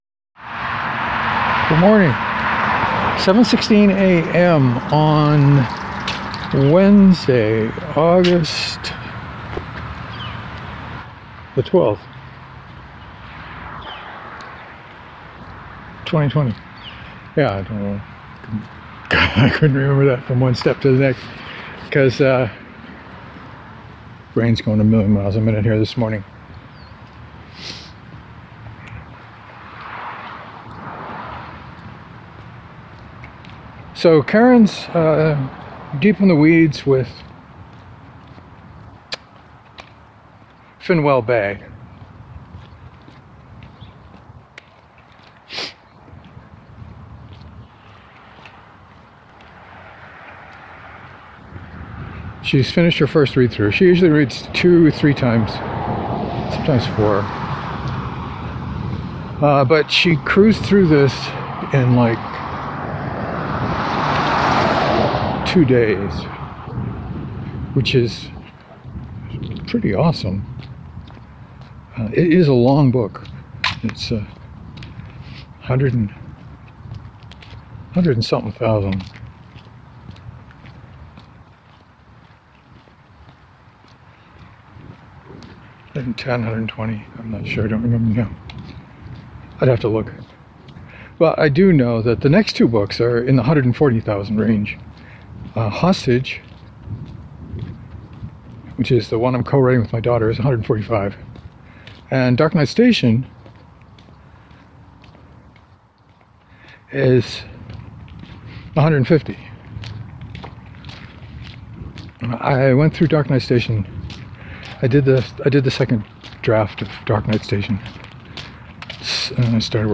It started out almost blustery but died out by the time I got around the loop.